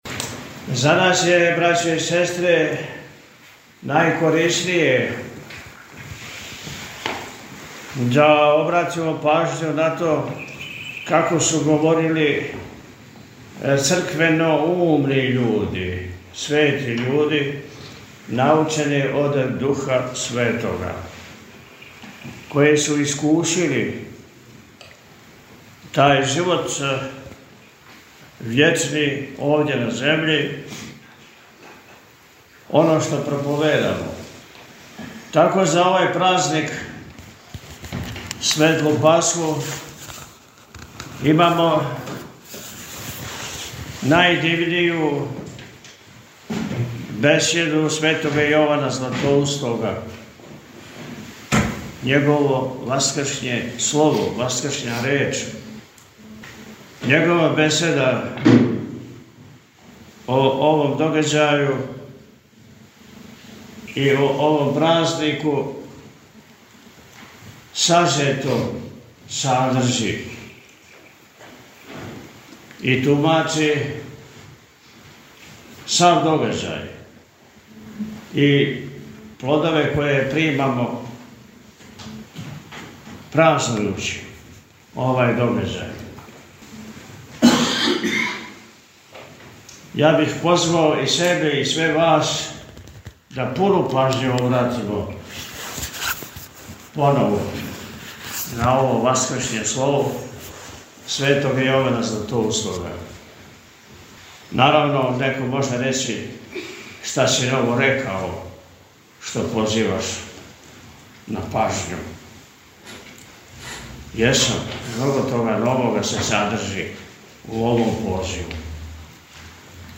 Честитајући празник Васкрсења Христовог Високопреосвећени поучио је сабрани верни народ пригодном пастирском беседом: – За нас је најкорисније да обратимо пажњу на то како су говорили црквеноумни људи, свети људи, научени од Духа Светога, који су искусили тај Живот Вечни овде на земљи, оно што проповедамо.
Pljevlja-Beseda-Ponedeljak.mp3